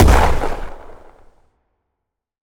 explosion_small_03.wav